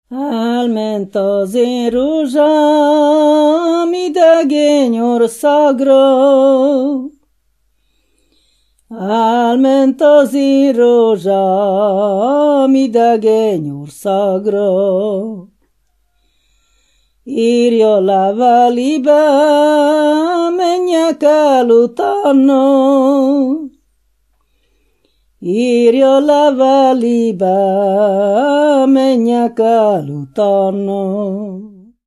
Moldva és Bukovina - Moldva - Szitás
ének
Műfaj: Keserves
Stílus: 7. Régies kisambitusú dallamok
Kadencia: 5 (4) 1 1